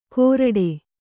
♪ koraḍe